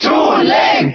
Category:Crowd cheers (SSBB) You cannot overwrite this file.
Toon_Link_Cheer_German_SSBB.ogg.mp3